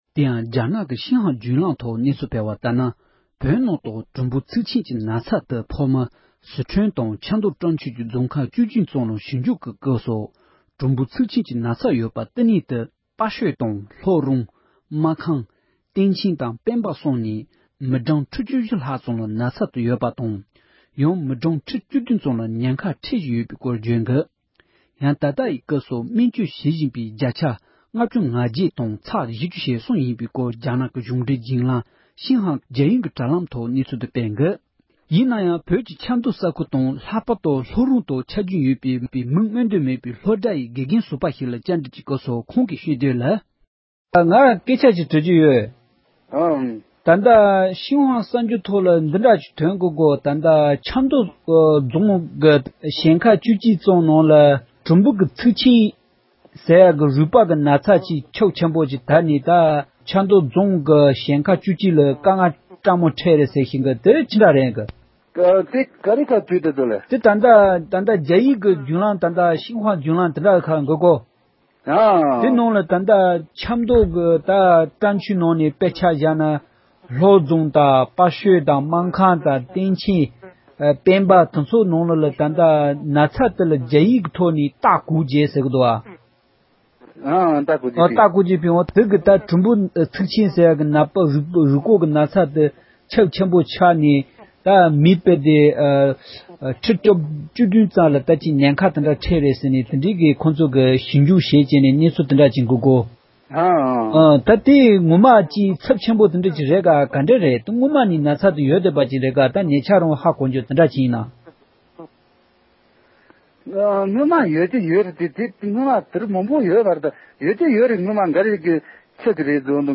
ས་གནས་ཡུལ་མི་ཞིག་ལ་བཀའ་འདྲི་ཞུས་པ